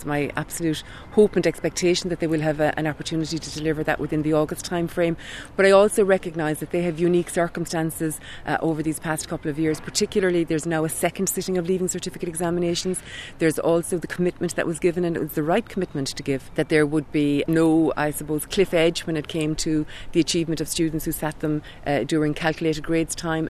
Minister Norma Foley says she’s hopeful results day will be earlier than last year: